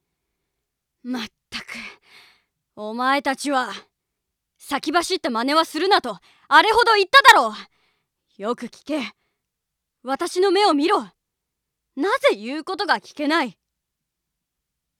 （年下の子どもたちが先走るのを怒る先生的な）